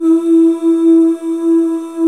Index of /90_sSampleCDs/Club-50 - Foundations Roland/VOX_xMaleOoz&Ahz/VOX_xMale Ooz 1M